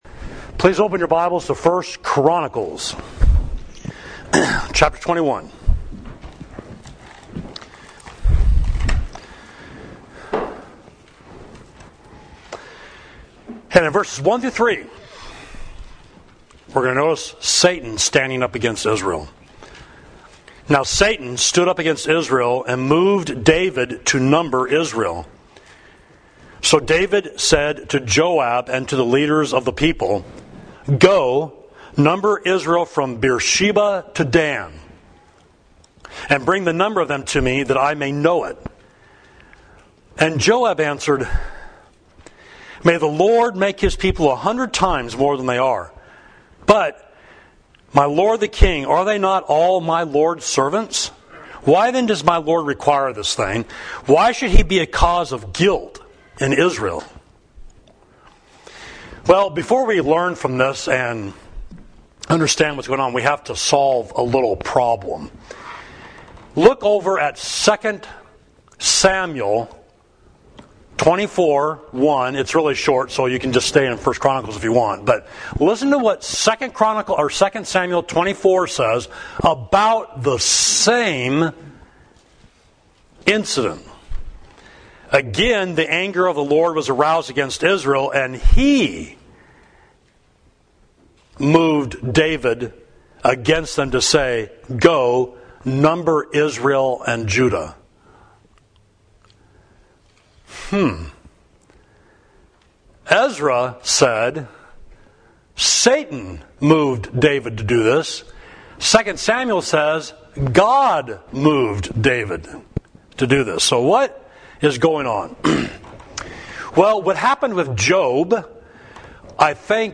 Sermon: Satan Stood Up Against Israel – Savage Street Church of Christ